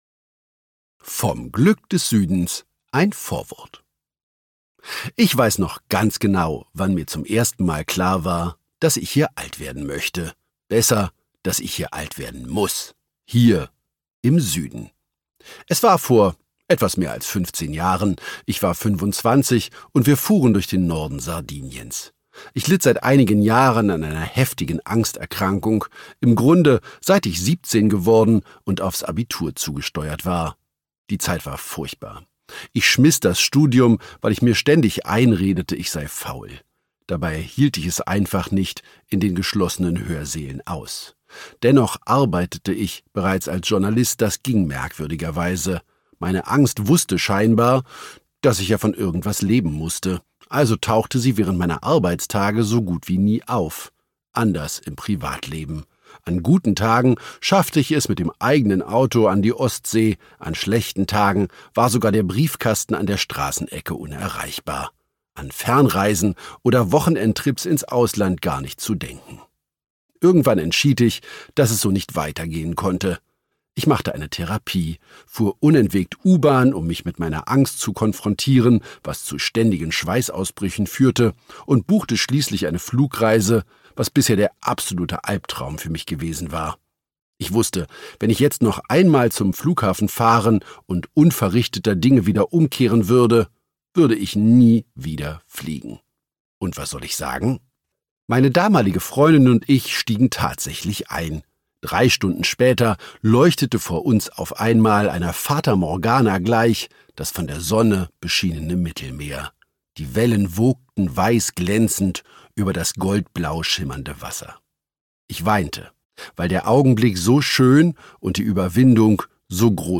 Schlagworte Auswandern • Autorenlesung • Gelassenheit • Griechenland • gutes Essen • Hundertjährige • Italien • Mittelmeer • SIESTA • Spanien • Wein